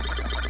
Sound of Ms. Pac-Man eating dots